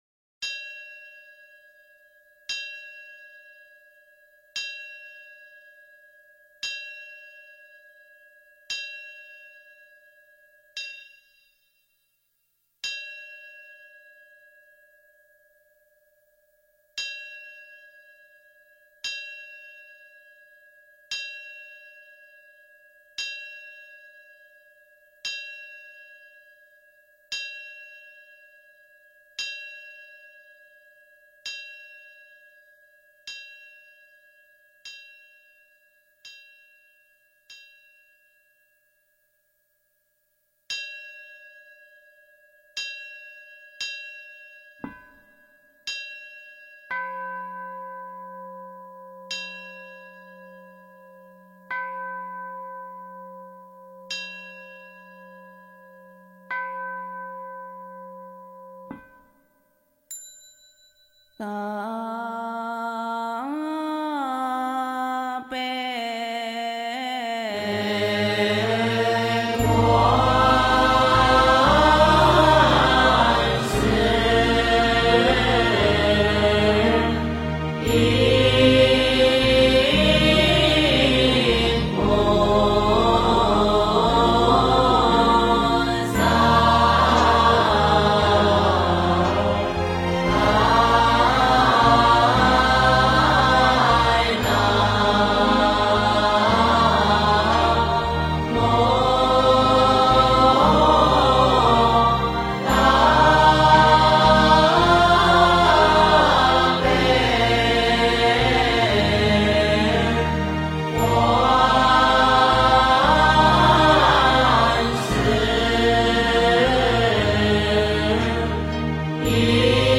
南无大悲观世音菩萨三称 诵经 南无大悲观世音菩萨三称--如是我闻 点我： 标签: 佛音 诵经 佛教音乐 返回列表 上一篇： 南无本师释迦牟尼佛(三称) 下一篇： 三皈依 相关文章 净一切眼疾陀罗尼 净一切眼疾陀罗尼--海涛法师...